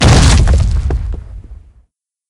Tiếng Nổ ngắn, vang dội
Hiệu ứng âm thanh 538 lượt xem 28/02/2026
Hiệu ứng âm thanh Tiếng Nổ ngắn - Chấn động, vang dội và đầy uy lực
Hiệu ứng âm thanh Tiếng nổ ngắn (Short Explosion sound effect) mô phỏng một vụ nổ diễn ra tức thì với cường độ âm thanh cực lớn. Đặc tính của âm thanh này là sự kết hợp giữa tiếng "Rầm" đanh thép, dứt khoát ở phần đầu và độ vang (reverb) sâu đậm ở phần cuối, tạo ra cảm giác về một nguồn năng lượng khổng lồ vừa được giải phóng trong một không gian rộng lớn.